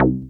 BASSFUNK.wav